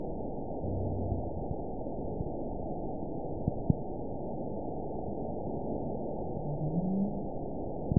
event 922524 date 01/25/25 time 19:46:53 GMT (3 months ago) score 9.47 location TSS-AB04 detected by nrw target species NRW annotations +NRW Spectrogram: Frequency (kHz) vs. Time (s) audio not available .wav